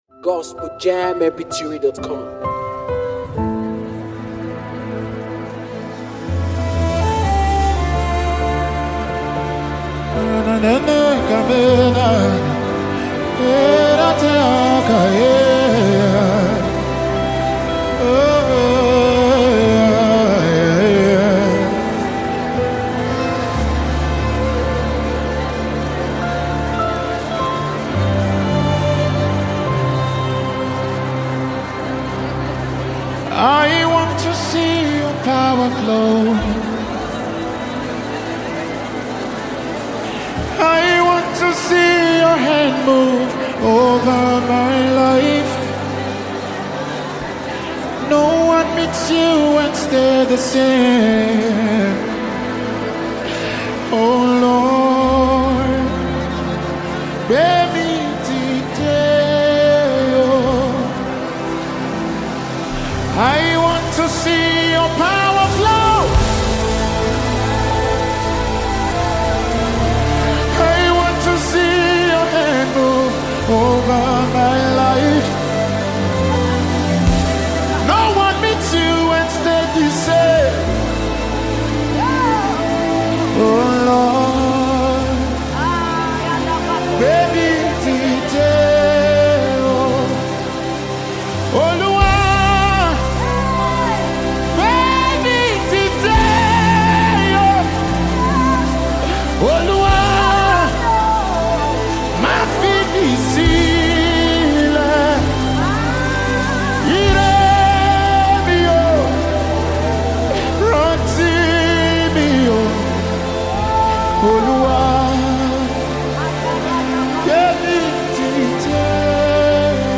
It’s positioned as a worship/inspirational gospel song